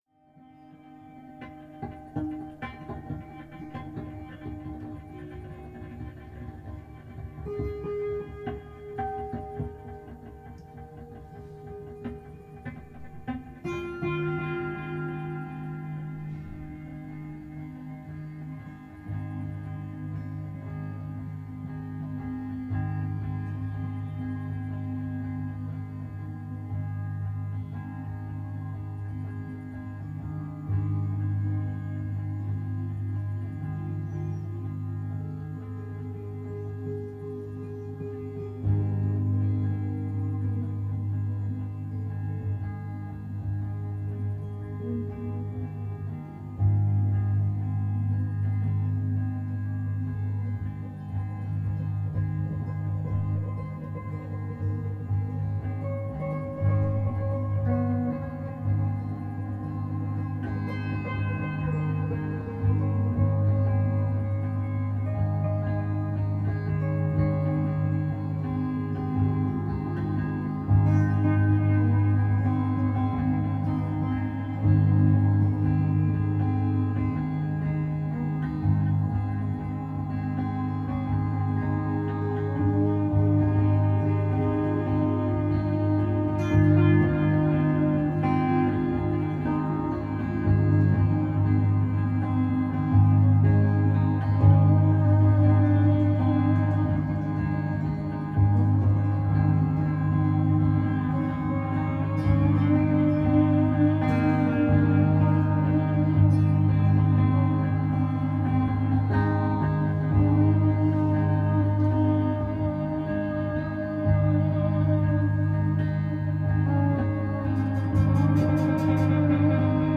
na área do folk rock psicadélico experimental e ambiental.